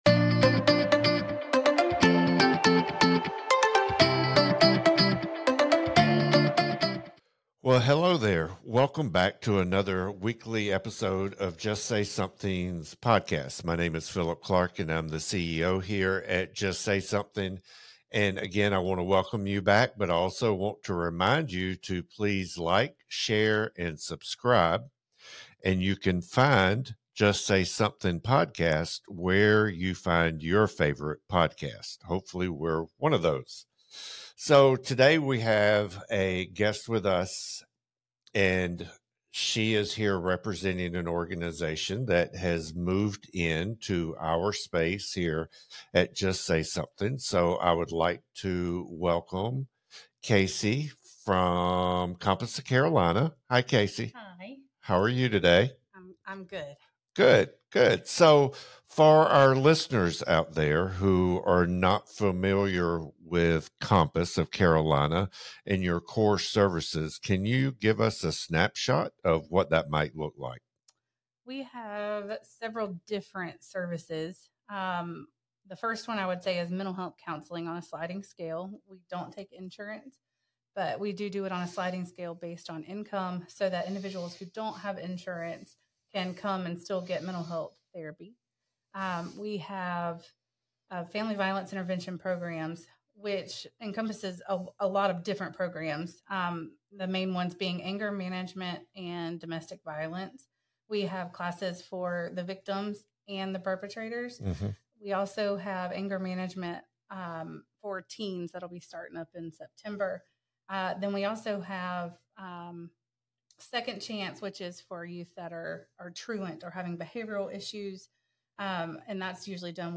Episode 73: Conversation with Compass of Carolina